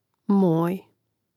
mȏj moj